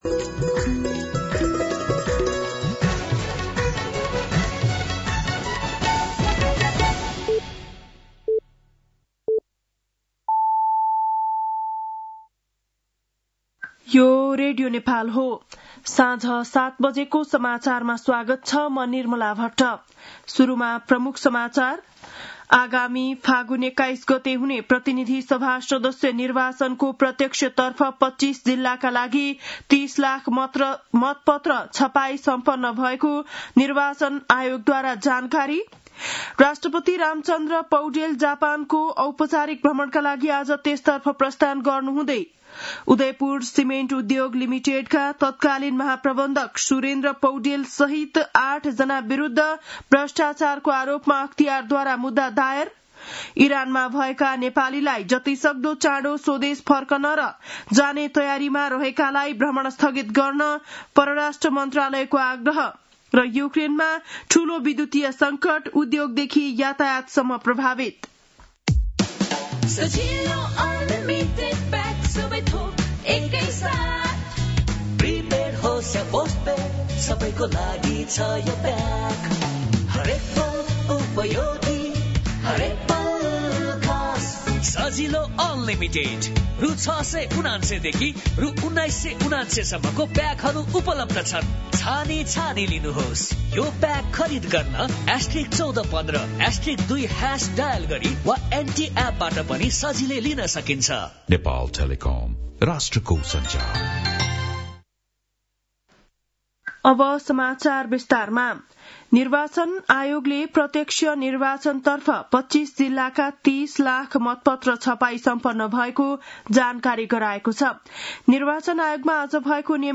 बेलुकी ७ बजेको नेपाली समाचार : १८ माघ , २०८२
7.-pm-nepali-news-.mp3